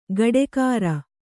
♪ gaḍekāra